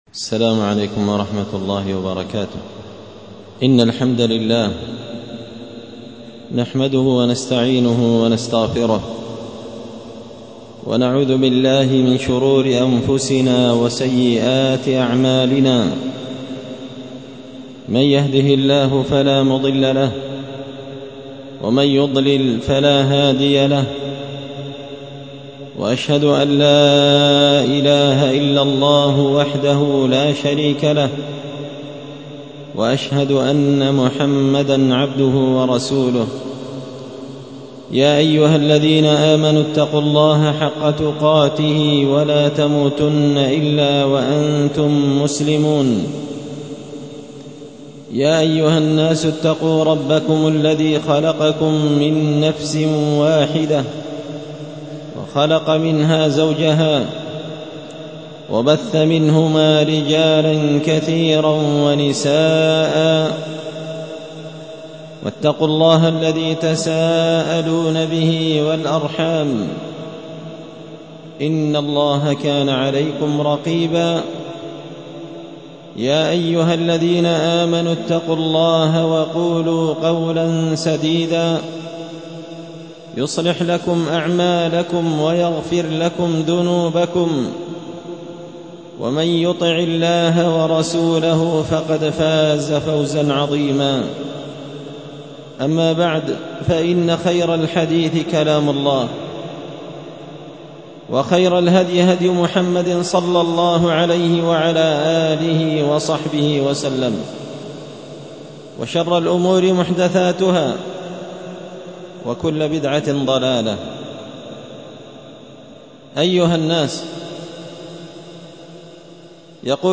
خطبة جمعة بعنوان – حرب من علام الغيوب على أهل هذه الذنوب
دار الحديث بمسجد الفرقان ـ قشن ـ المهرة ـ اليمن